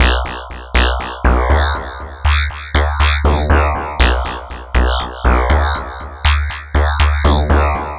FM Tweaker.wav